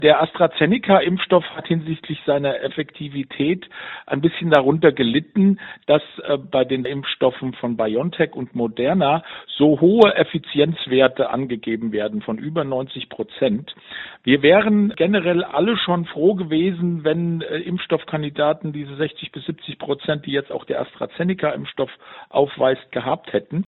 Virologe